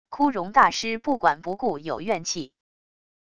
枯荣大师不管不顾有怨气wav音频生成系统WAV Audio Player